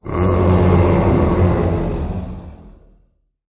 079-Monster01.mp3